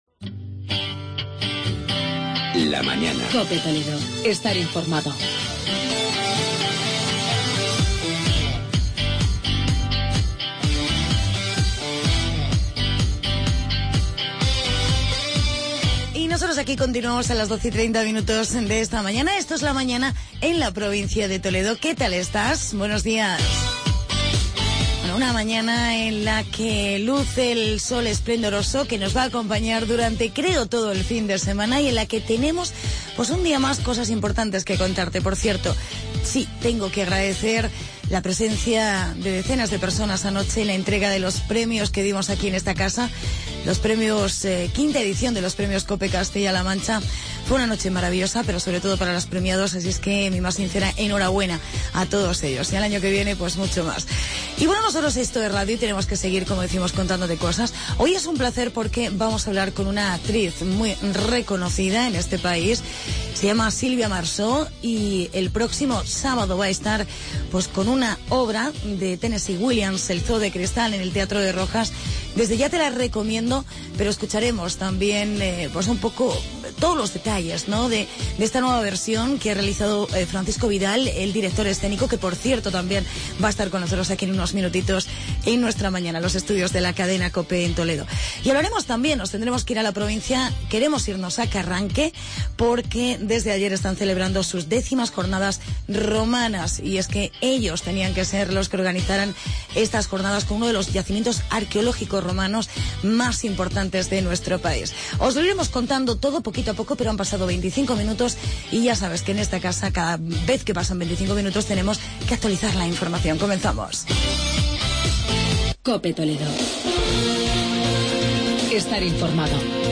Entrevista con la actriz Silvia Marsó